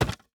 Drop Wood A.wav